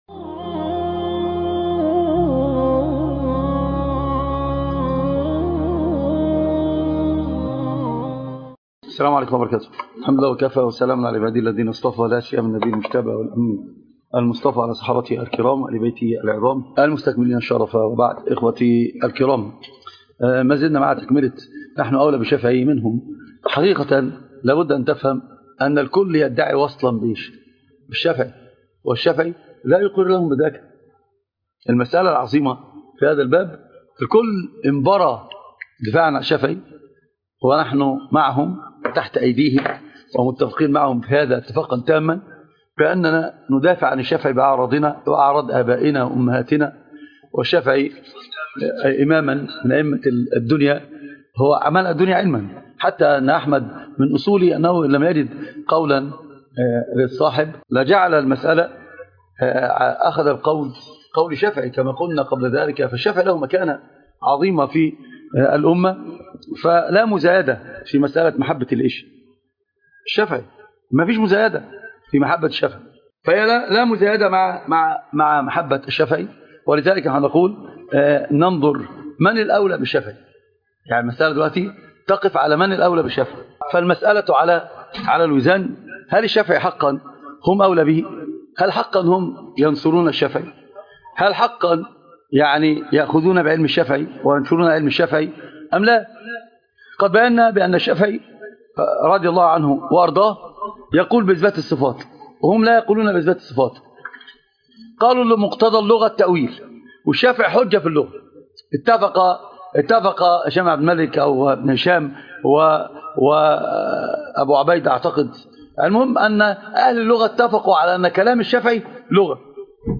محاضرة - نحن أولى بالشافعي منكم